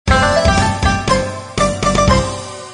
gameWin.mp3